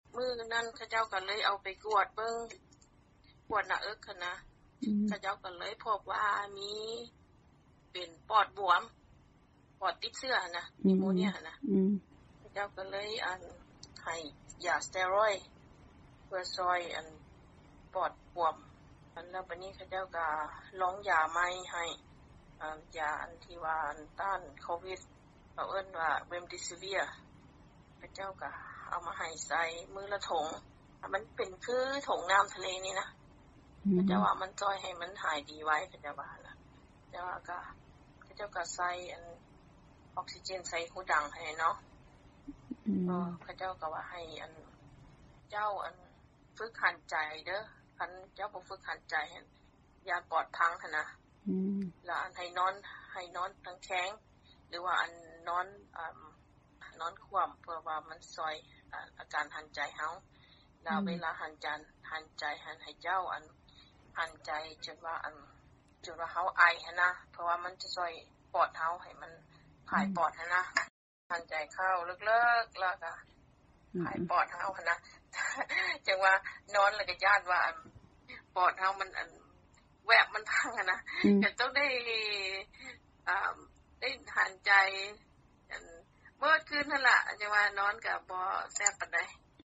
ສຽງ 1 ແມ່ຍິງທີ່ເປັນພະຍາດໂຄວິດ-19 ເລົ່າເຖິງການປິ່ນປົວຢູ່ໂຮງໝໍ